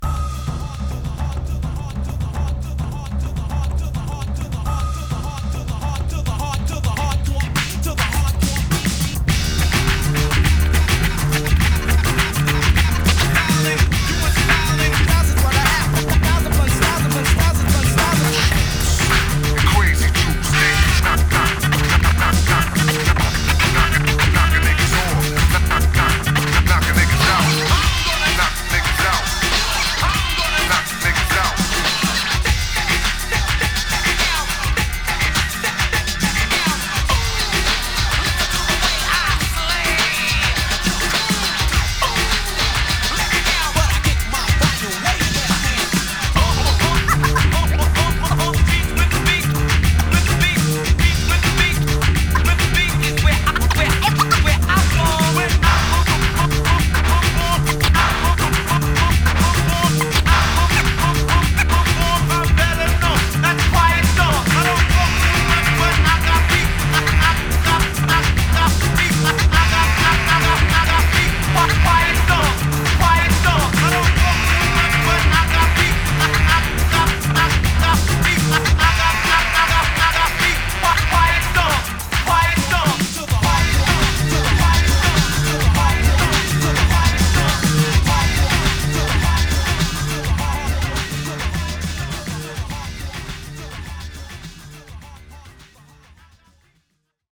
> JAZZY BREAK/ELECTRONICA/ABSTRACT
ヒップ・ホップの伝統を踏まえながら、サイケデリックな要素も持ち合わせた白昼夢のような傑作。"